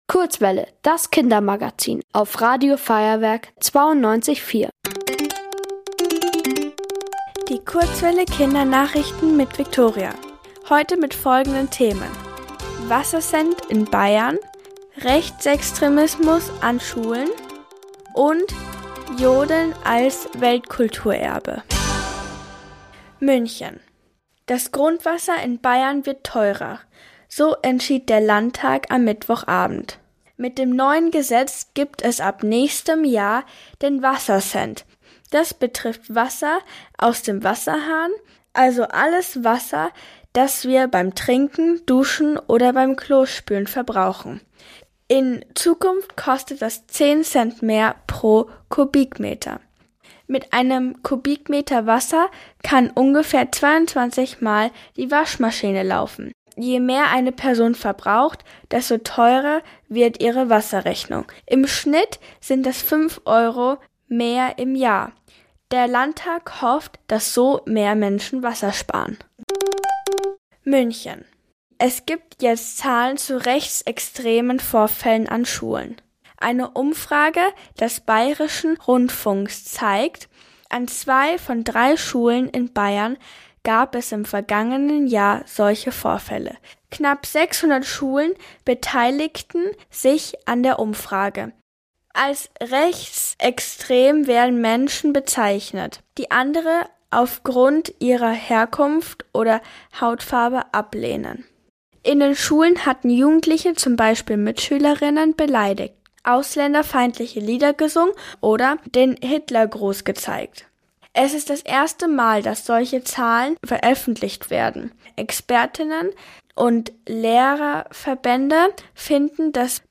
Die Kurzwelle Kindernachrichten vom 13.12.2025